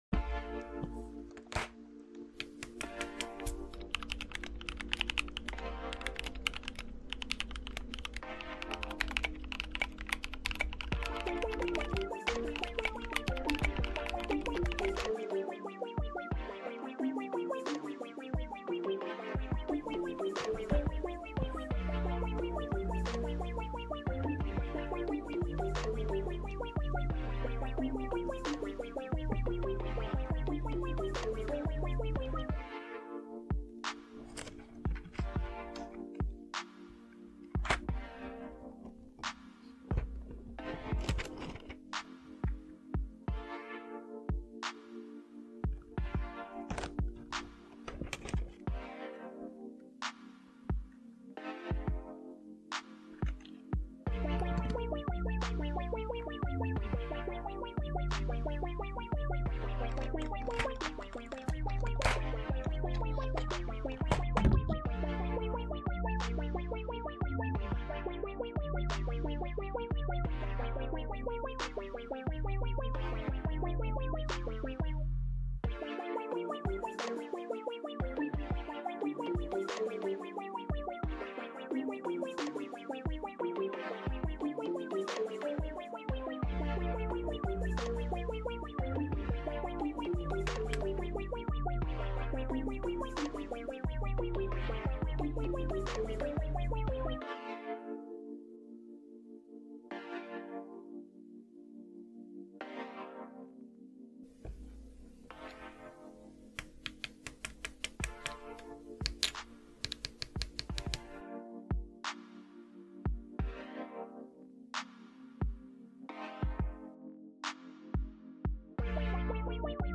Domikey White on Black SA Keycaps Review ASMR